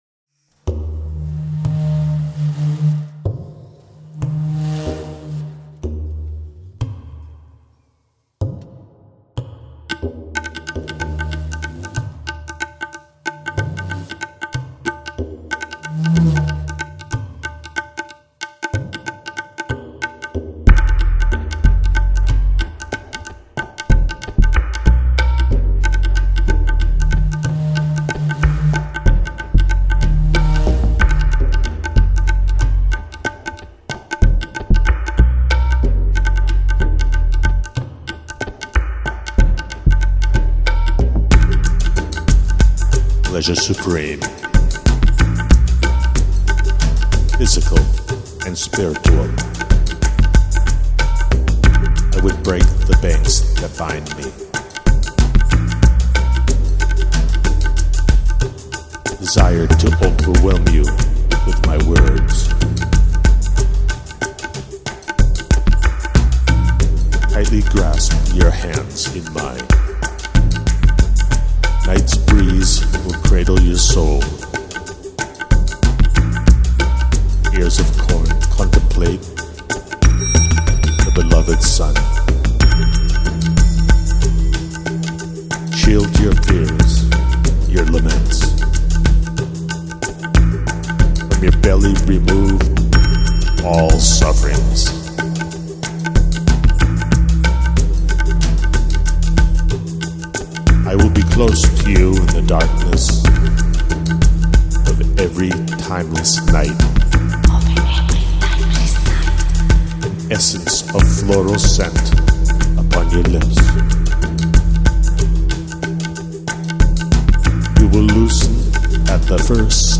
Жанр: Electronic, Downtempo, Lounge, Chill Out